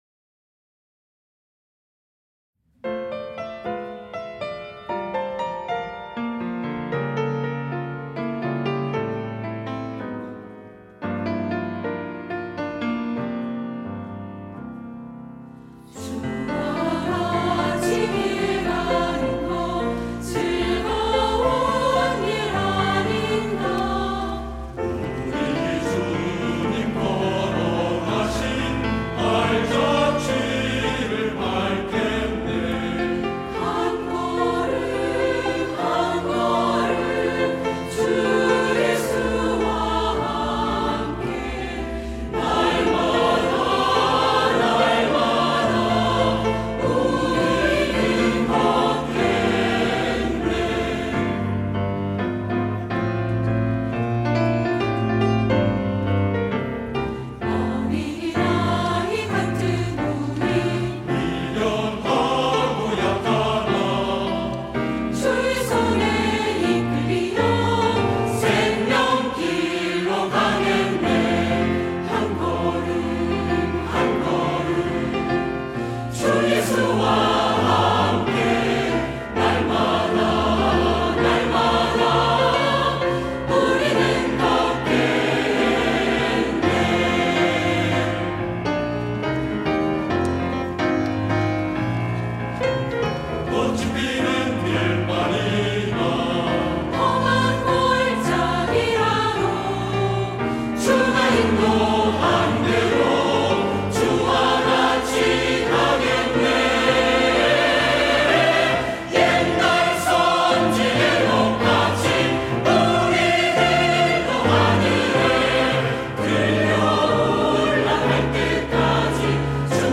할렐루야(주일2부) - 주와 같이 길 가는 것
찬양대